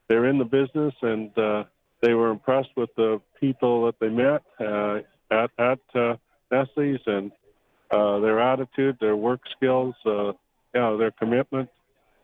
Harrison says while the sale is not final yet, and he can’t reveal any details about the meeting, the duo appear to have big plans for the facility.